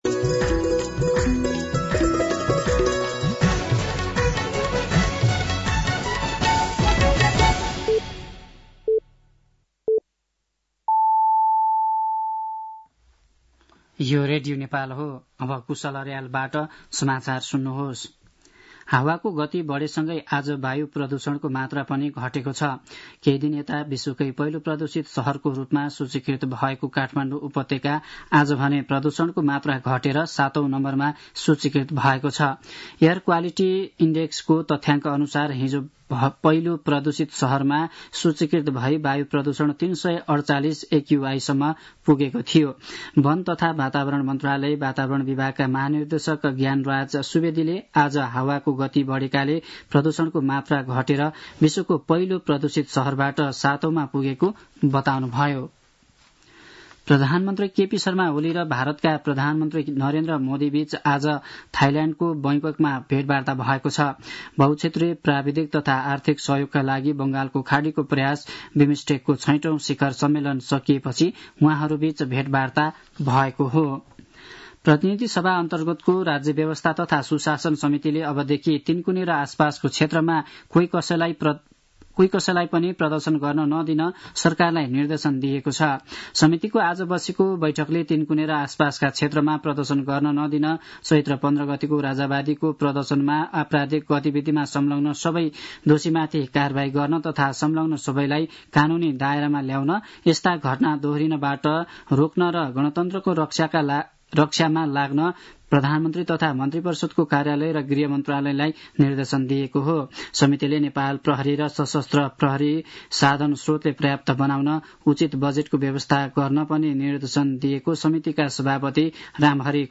साँझ ५ बजेको नेपाली समाचार : २२ चैत , २०८१